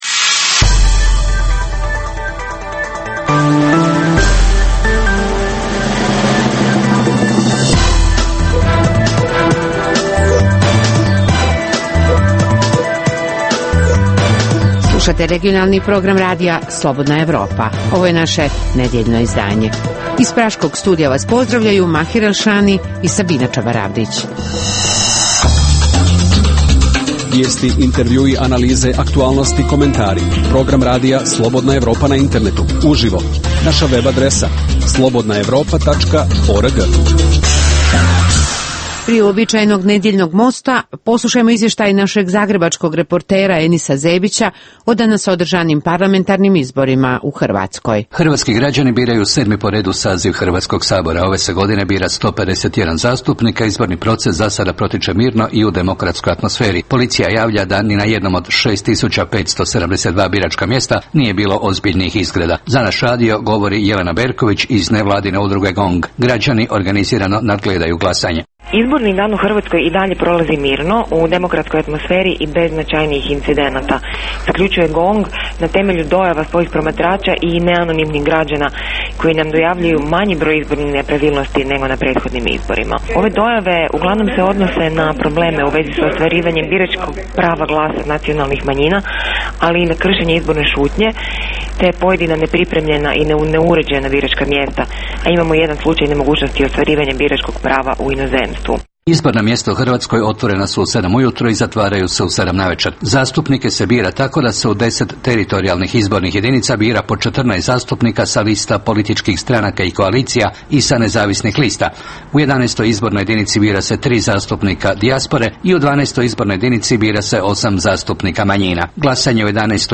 ugledni sagovornici iz regiona diskutuju o aktuelnim temama